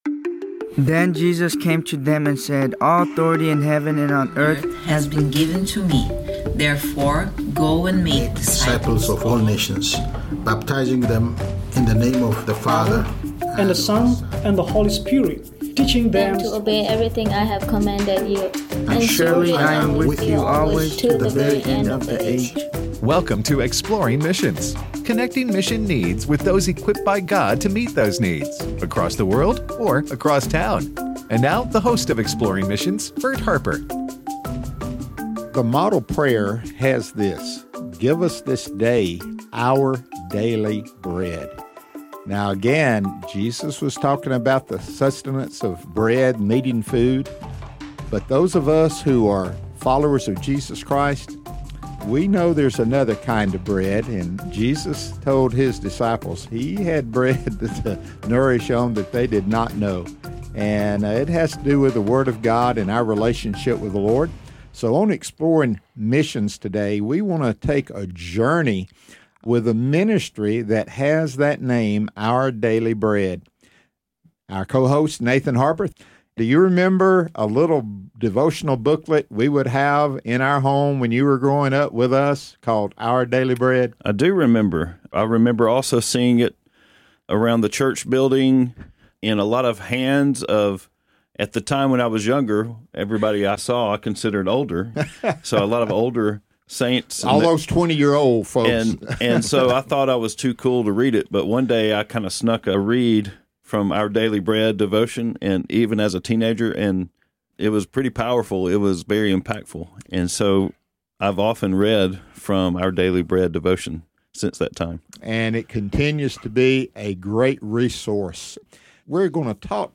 Our Daily Bread: A Conversation